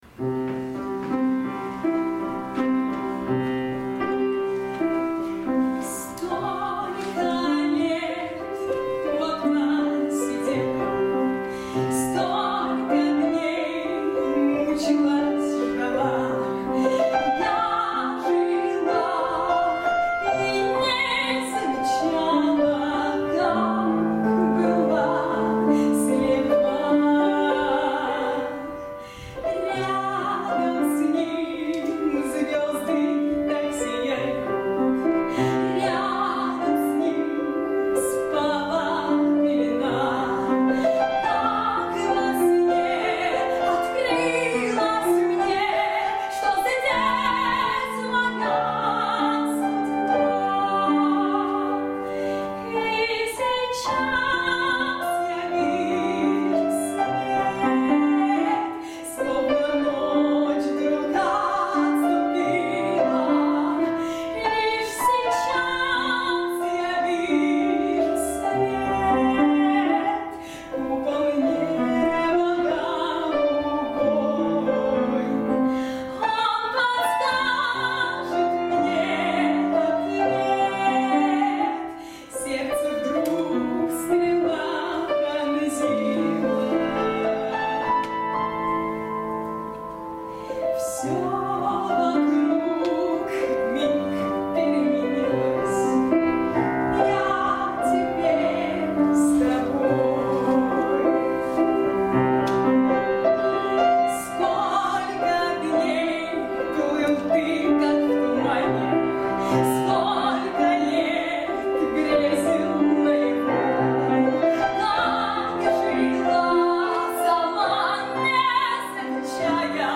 вокал соло